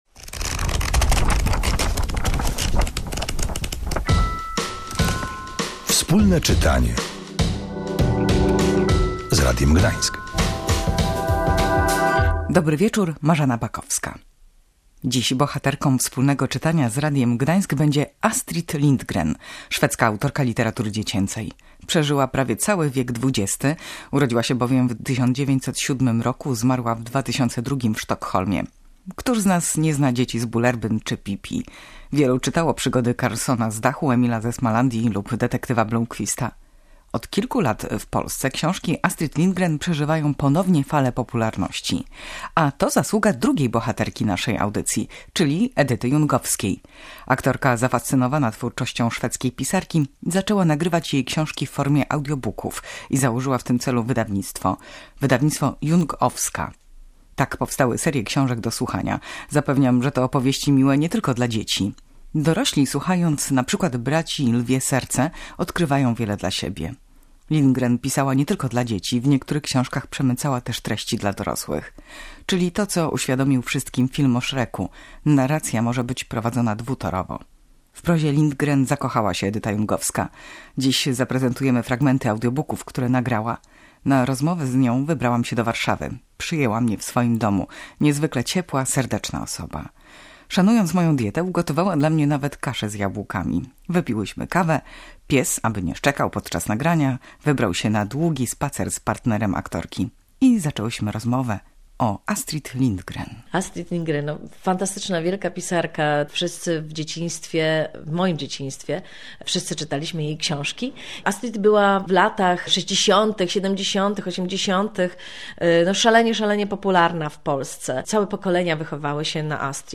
W czasie audycji można posłuchać fragmentów książek czytanych przez aktorkę. Opowiada także o życiu szwedzkiej pisarki, pokazuje jak życie nieszablonowej Astrid wpłynęło na jej książki.